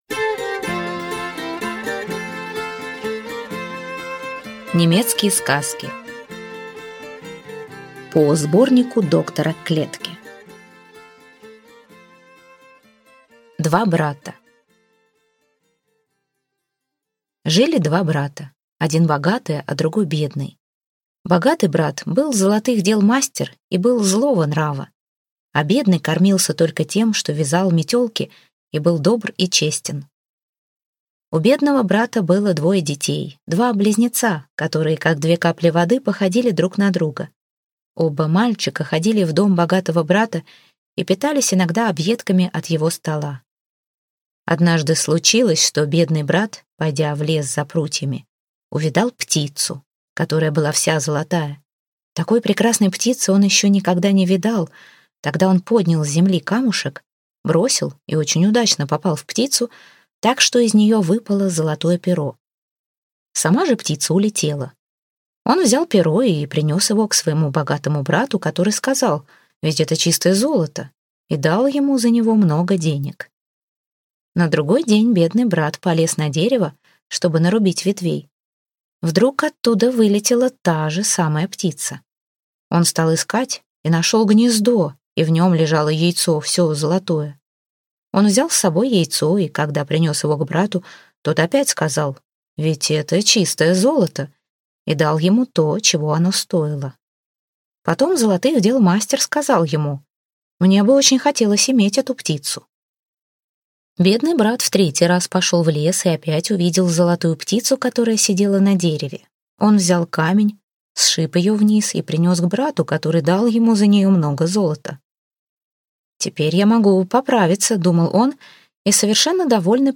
Аудиокнига Немецкие cказки | Библиотека аудиокниг